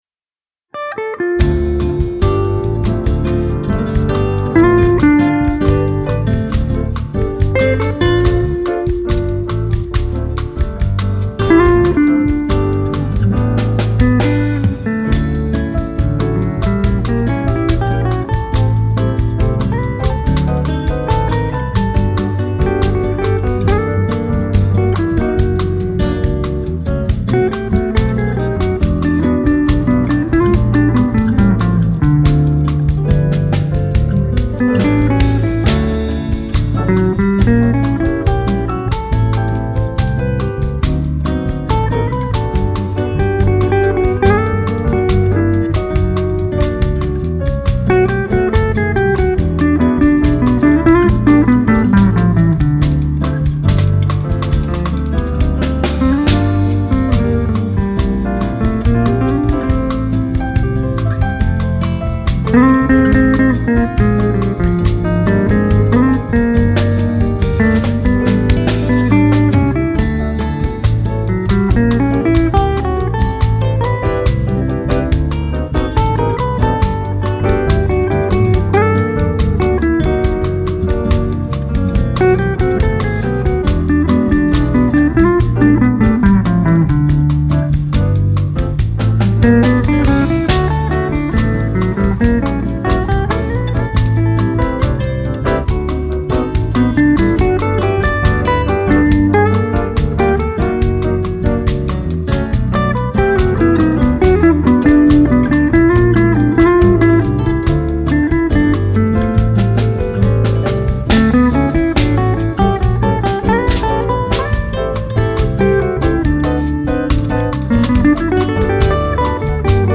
761   06:20:00   Faixa:     Bossa nova
Sax Soprano